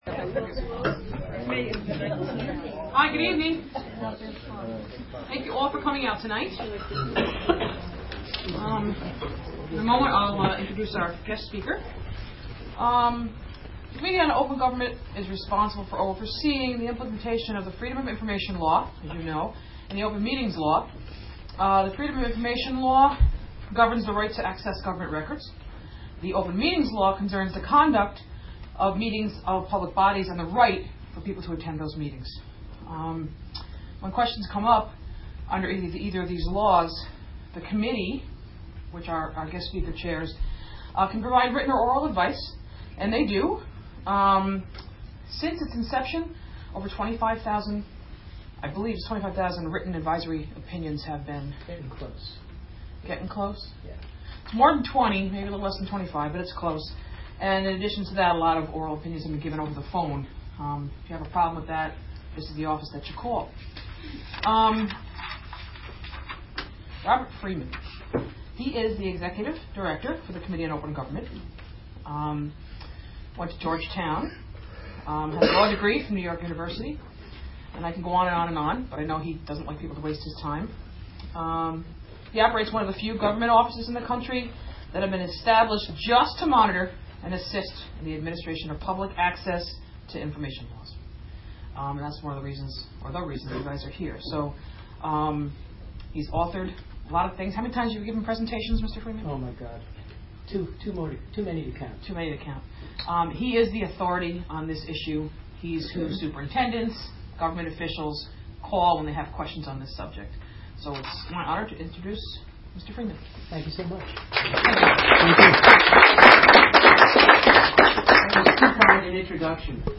(Audio) Nov 19, 2009 artists Town of Cairo, NY From WGXC Online Radio live broadcast.